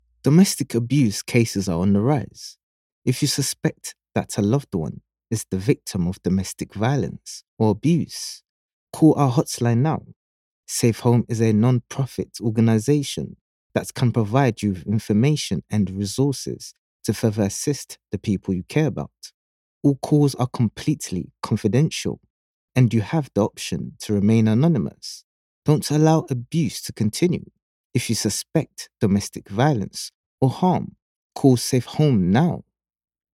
Best Male Voice Over Actors In March 2026
English (Caribbean)
Yng Adult (18-29) | Adult (30-50)